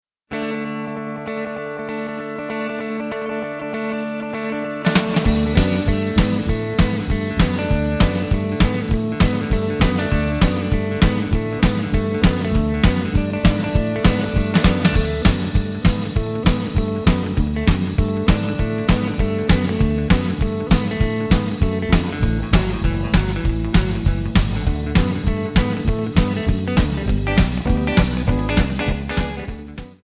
Backing track files: 1980s (763)
Buy With Backing Vocals.
Buy With Lead vocal (to learn the song).